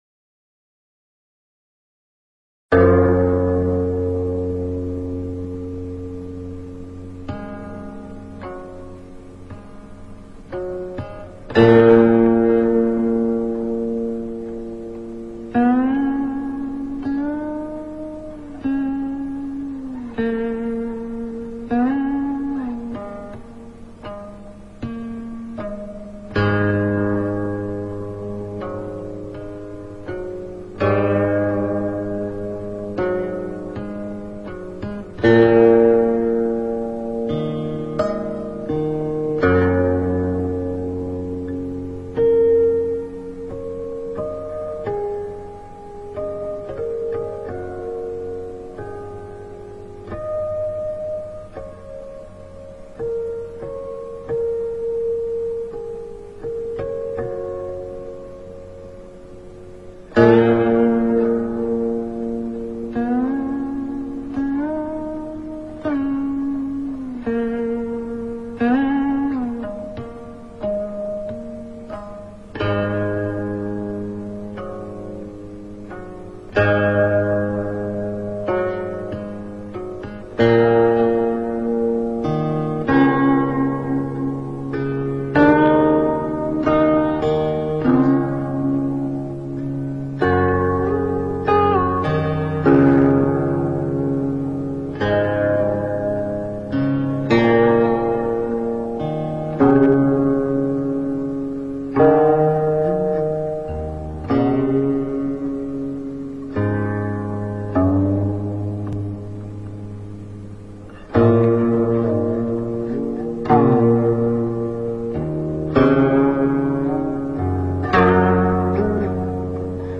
普庵咒--古琴
普庵咒--古琴 冥想 普庵咒--古琴 点我： 标签: 佛音 冥想 佛教音乐 返回列表 上一篇： 龙朔操--古琴 下一篇： 卧龙吟--古琴 相关文章 黄财神--果果乐队 黄财神--果果乐队...